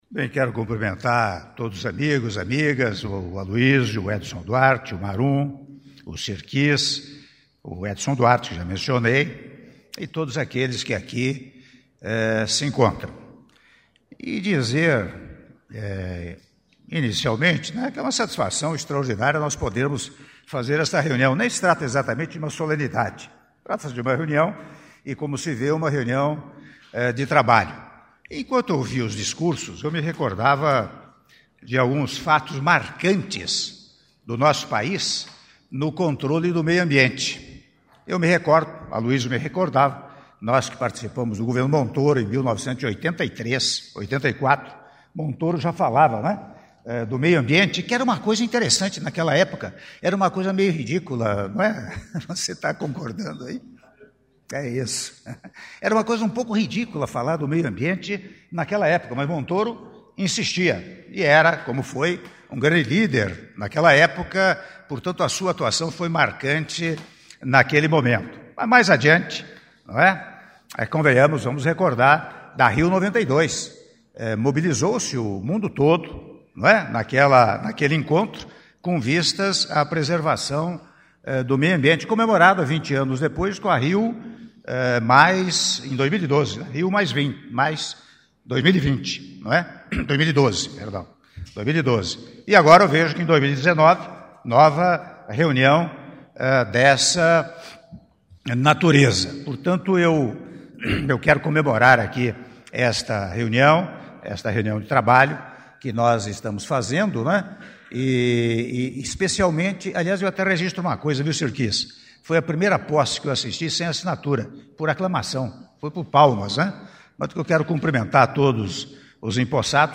Áudio do discurso do Presidente da República, Michel Temer, durante Reunião do Fórum Brasileiro de Mudança do Clima - Brasília/DF - (06min36s)